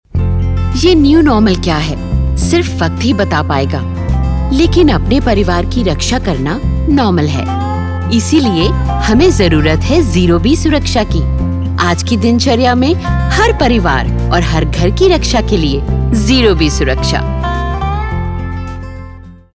Professional Hindi and english artiste
voice over recordings